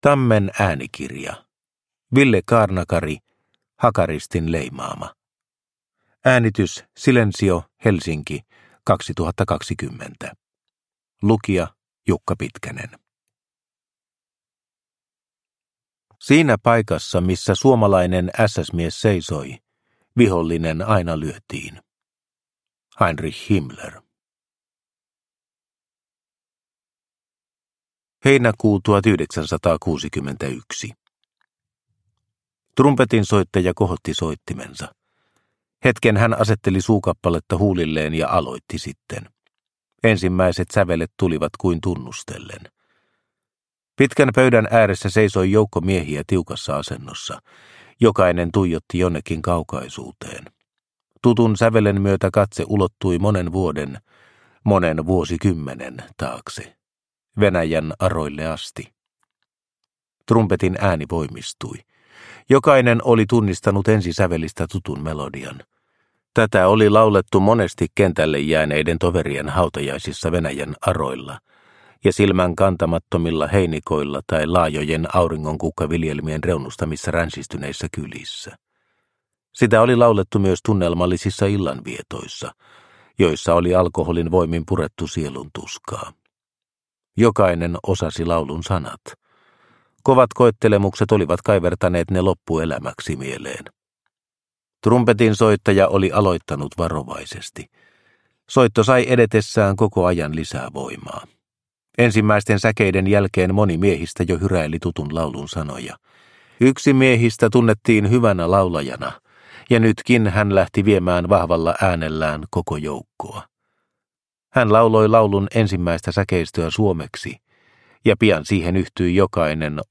Hakaristin leimaama – Ljudbok – Laddas ner